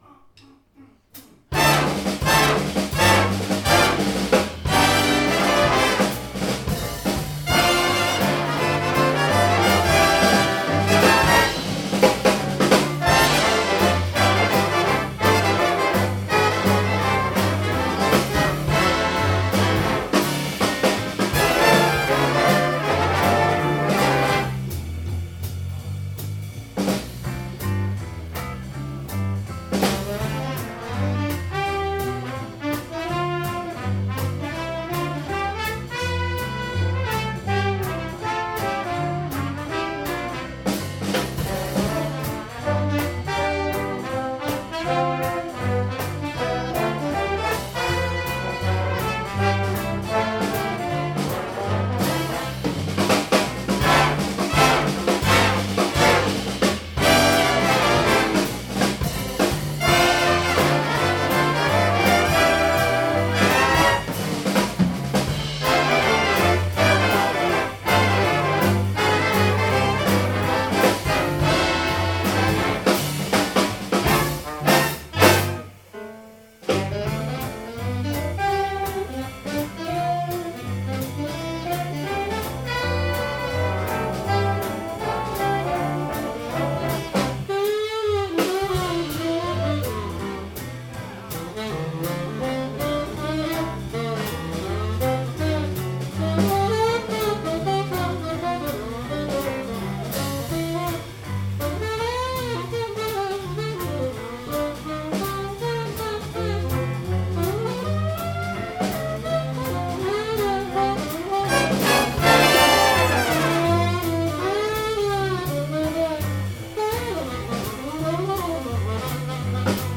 Galleri - Jazz in a Blue Funky Space oktober 2014
- Hayburner Big Band 26. oktober 2014